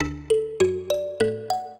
mbira
minuet0-12.wav